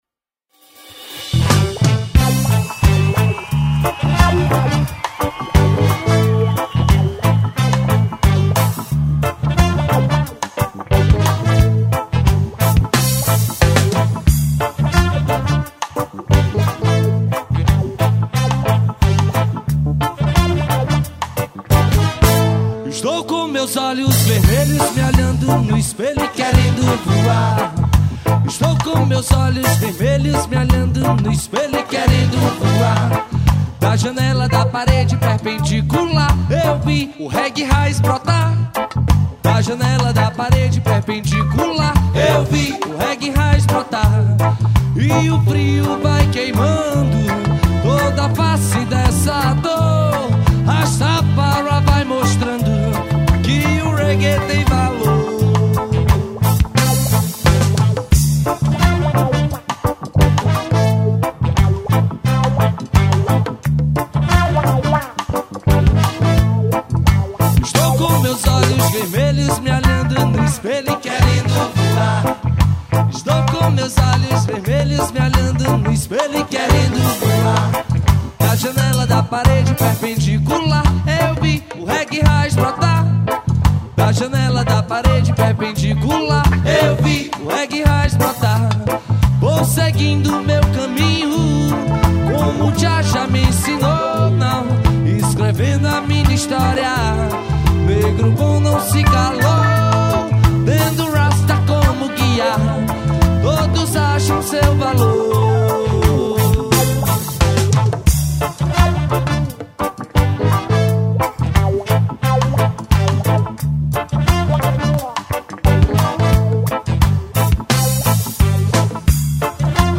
AO VIVO
2124   03:56:00   Faixa:     Reggae
Guitarra, Voz
Escaleta, Teclados
Bateria
Baixo Elétrico 6
Trompete, Vocal
Sax Alto